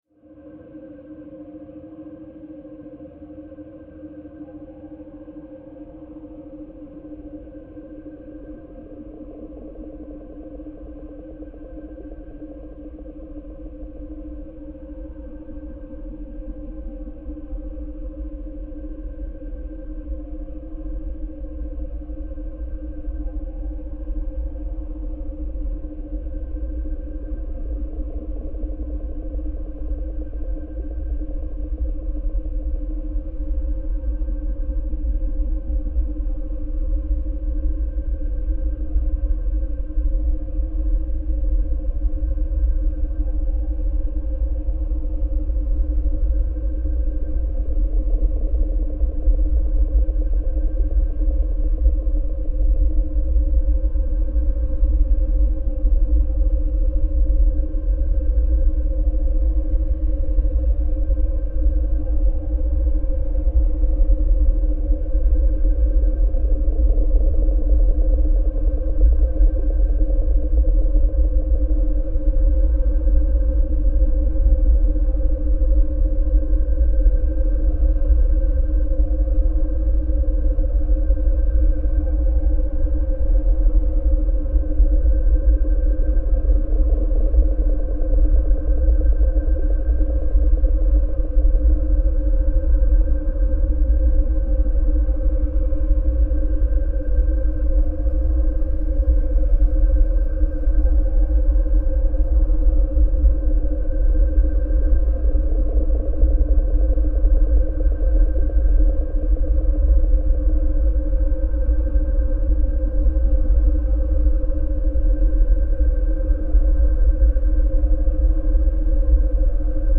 Ghana pond soundscape reimagined